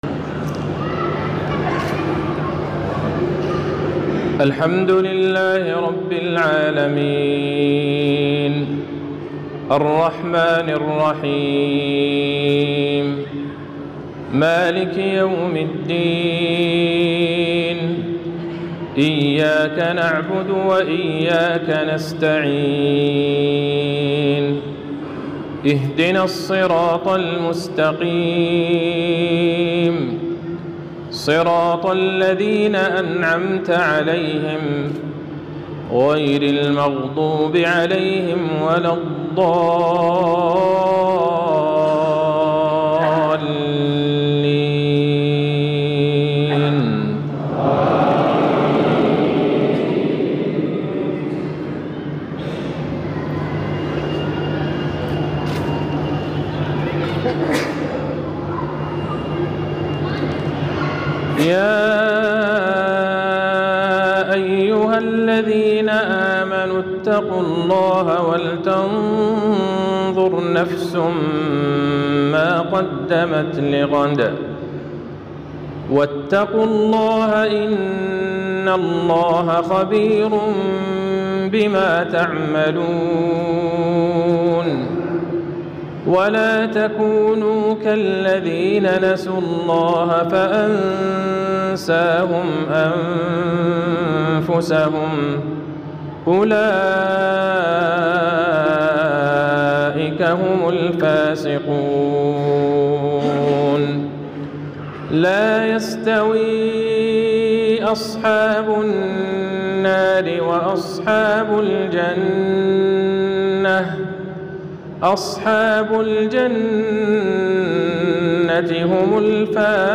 صلاة العشاء للشيخ عبدالله البعيجان ٧-٥-١٤٤٦هـ في الهند > تلاوات و جهود الشيخ عبدالله البعيجان > تلاوات وجهود أئمة الحرم النبوي خارج الحرم > المزيد - تلاوات الحرمين